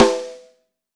• Tight Acoustic Snare Sound B Key 42.wav
Royality free snare drum tuned to the B note. Loudest frequency: 1204Hz
tight-acoustic-snare-sound-b-key-42-C1W.wav